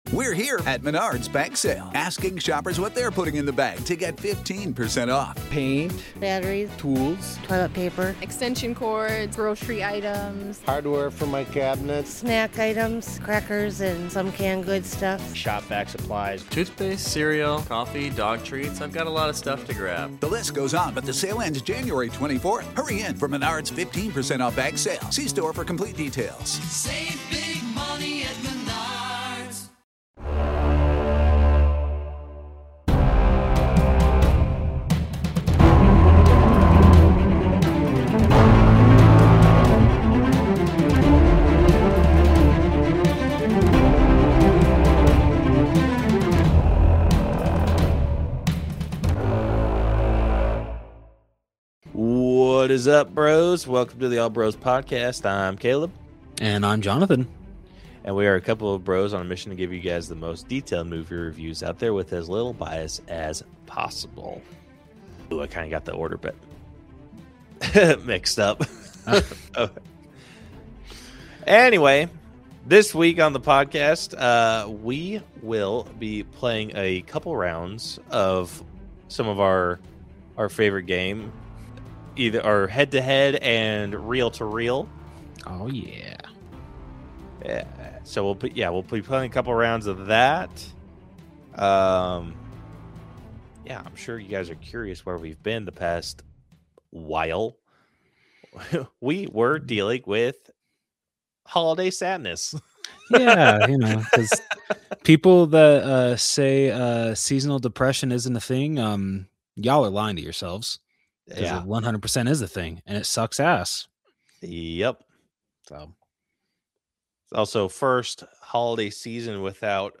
The All Bros are two bros that enjoy a good film and all things superhero-related. They talk about all the things that they find interesting in the world of film and also talk about all their collectible purchases like Funko Pops, Blu-Ray Steelbooks, and also breakdown movies that theyve seen to give them a final grade.